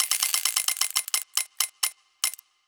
Prize Wheel Spin 2 (long).wav